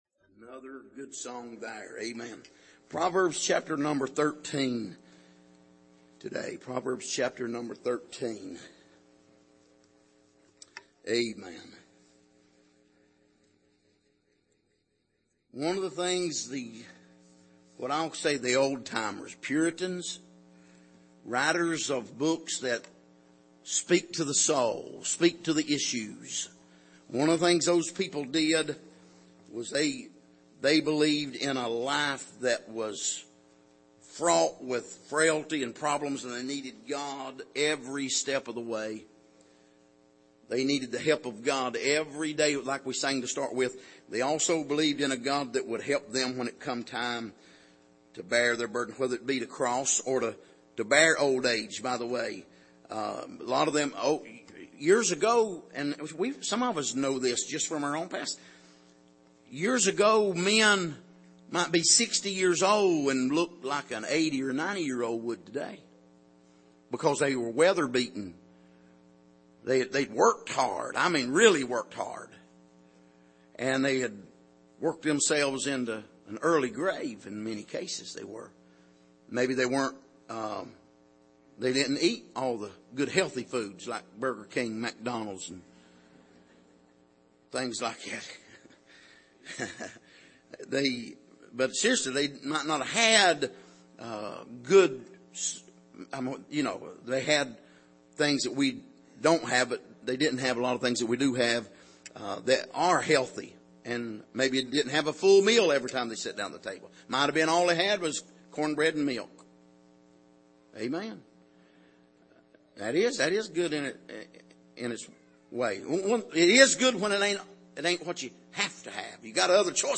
Passage: Proverbs 13:10-17 Service: Sunday Evening